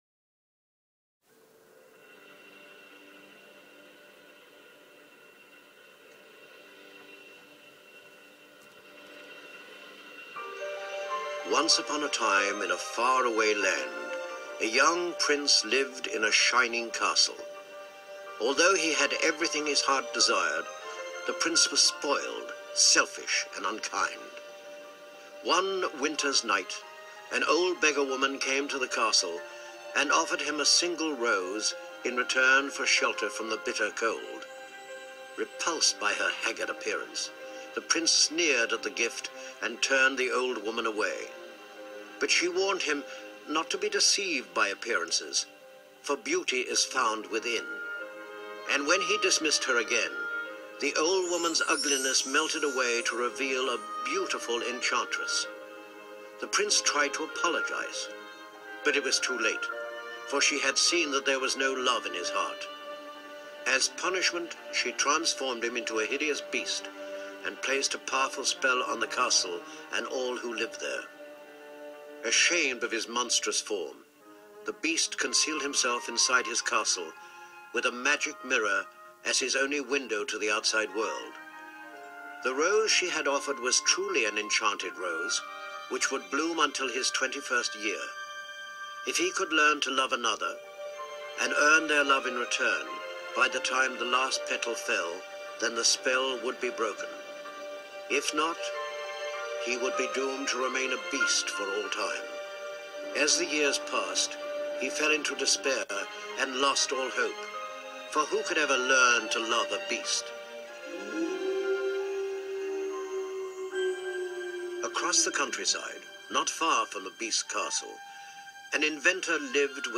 In 2004, Roy Dotrice narrated the Disney version of 'Beauty and the Beast' as part of the Storyteller Series.